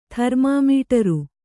tharmāmīṭaru